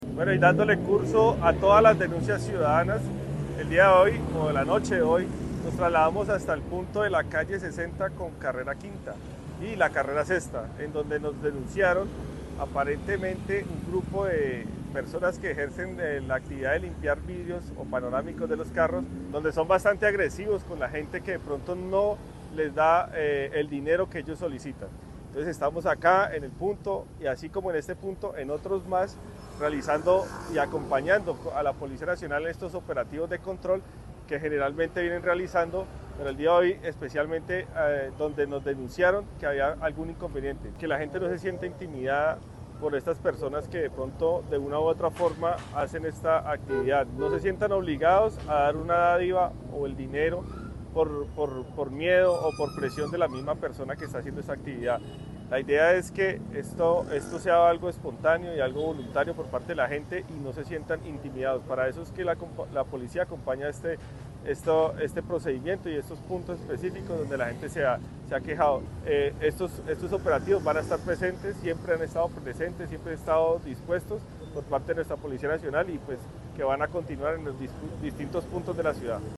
Escuche las declaraciones de Sergio Saavedra,  director de Seguridad y Convivencia Ciudadana:
SERGIO-SAAVEDRA-Director-de-seguridad-y-convivencia-ciudadana-1.mp3